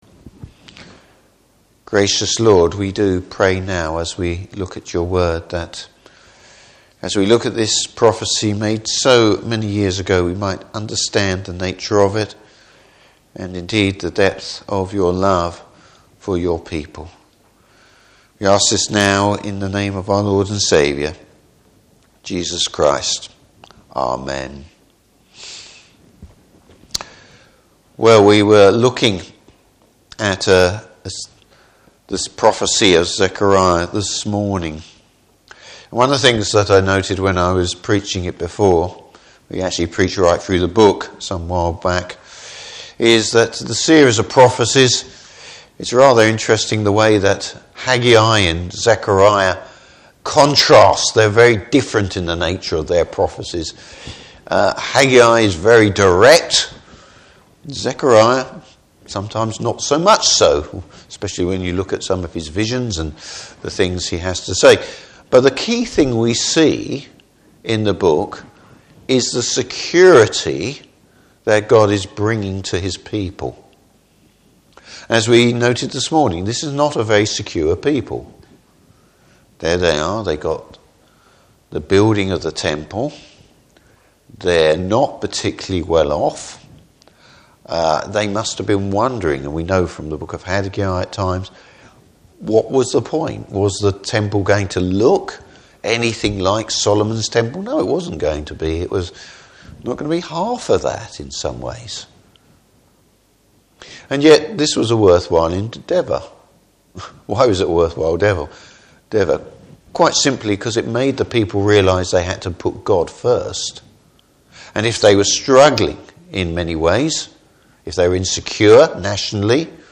Service Type: Evening Service The need for true repentance.